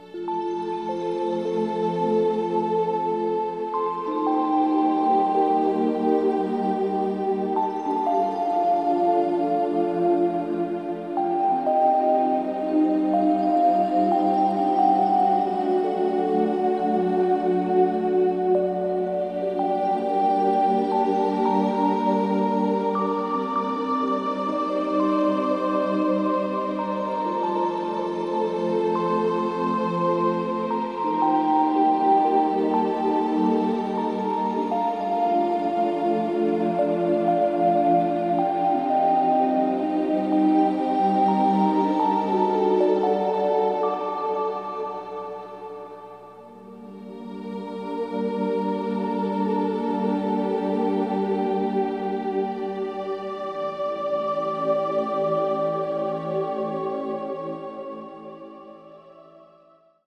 Very ambient and soothing.